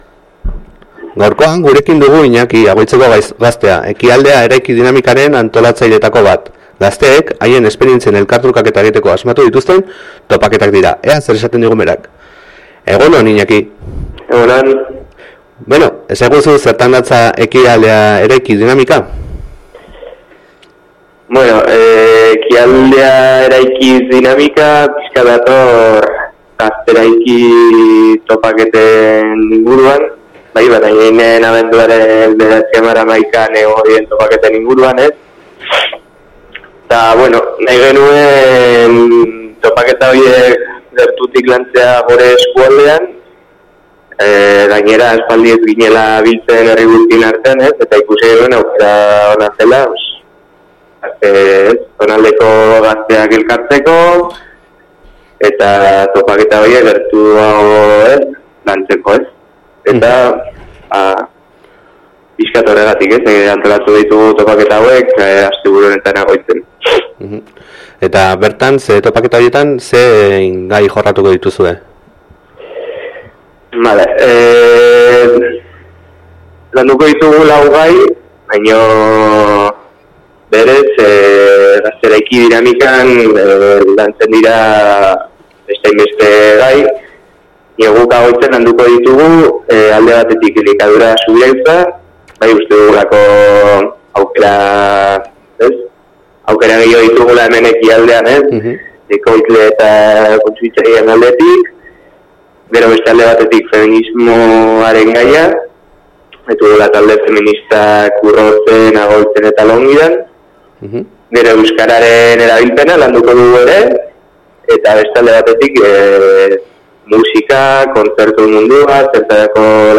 Ekialdea eraiki topaketen inguruko elkarrizketa | Irati Irratia